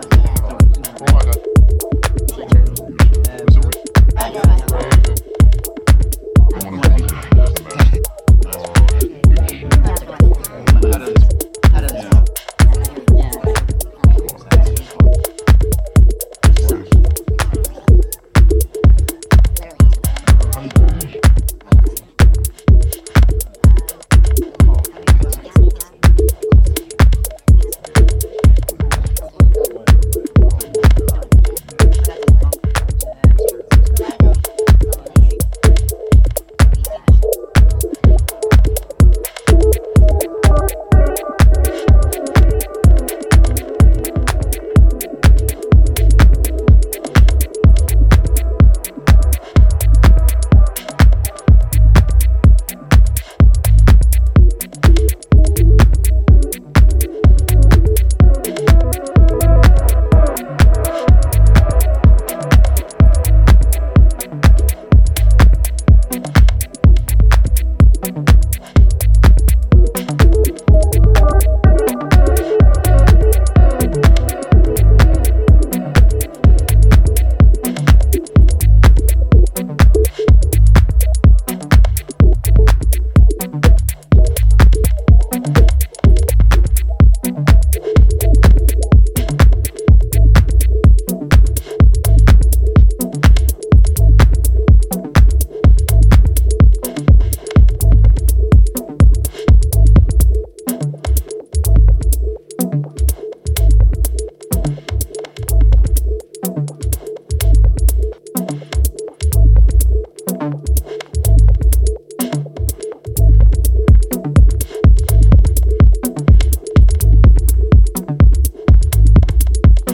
New club killer